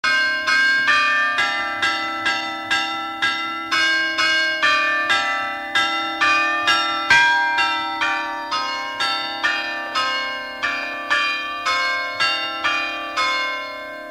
Air de carillon
instrumental
Pièce musicale inédite